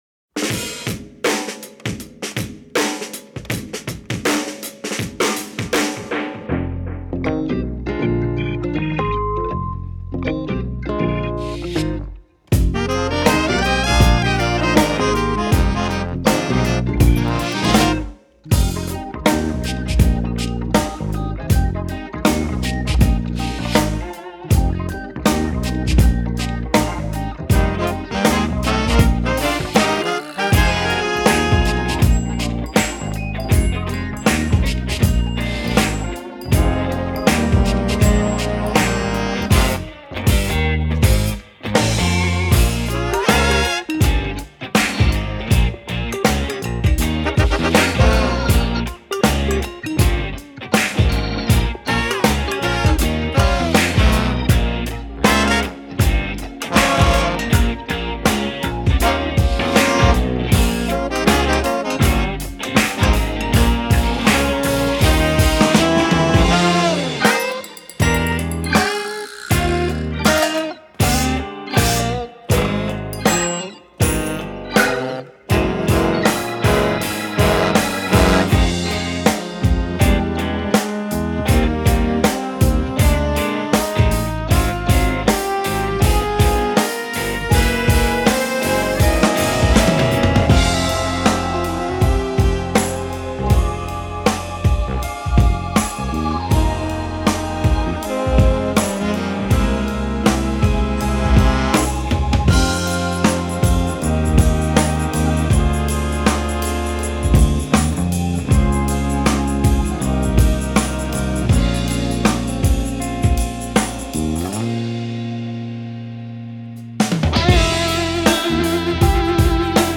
Bläser: Alt-Sax, Tenor-Sax, Trompete, Posaune